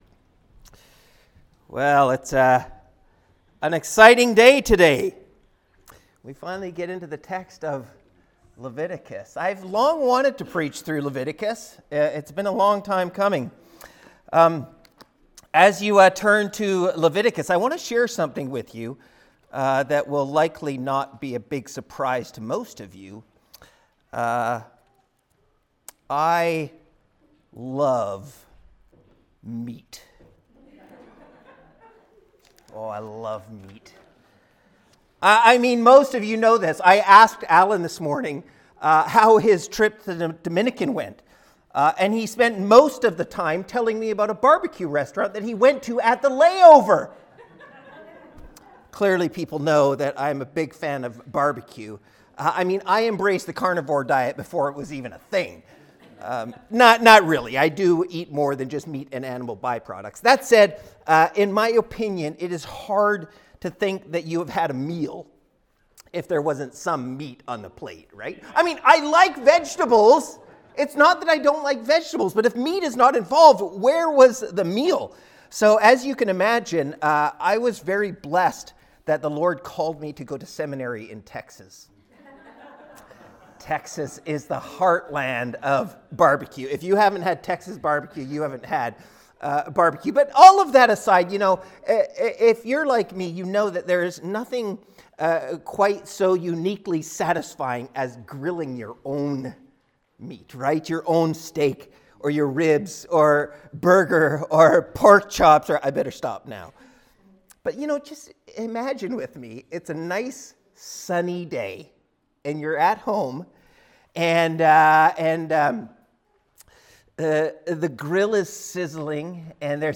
Halifax Reformed Baptist Sermons